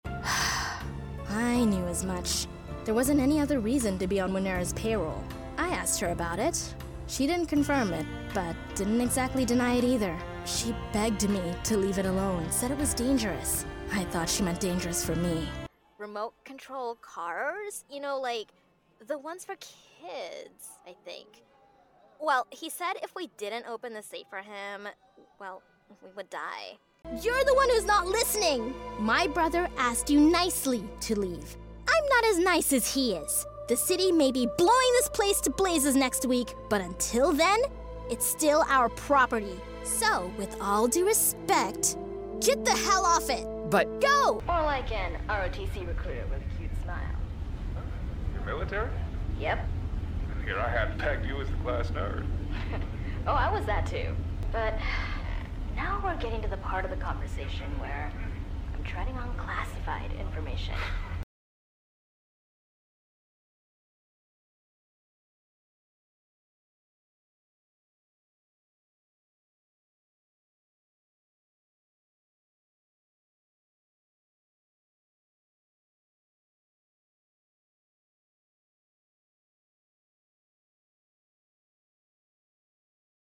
standard us | character
USAccent.mp3